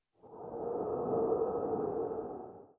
scpcb-godot/SFX/Ambient/General/Ambient8.ogg at aea7107a7b1dd01d04c1d874c481d3a4a12498cb
Ambient8.ogg